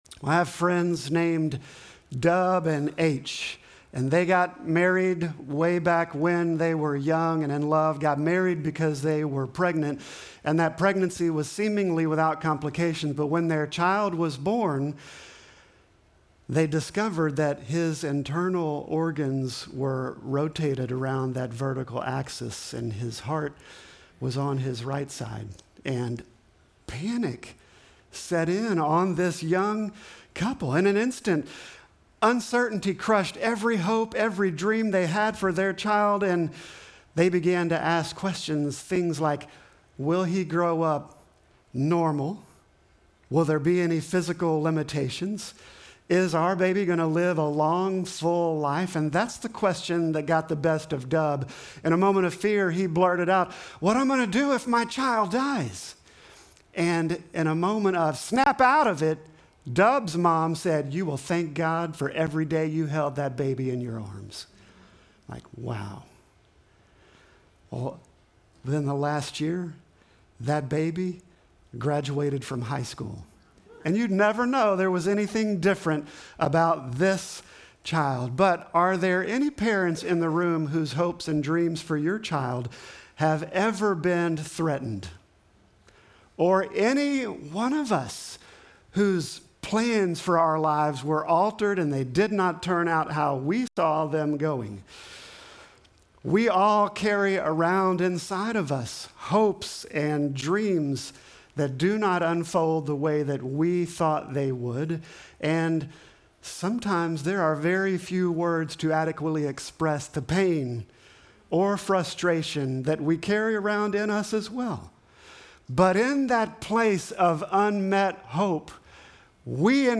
Sermon text: Luke 1:5-25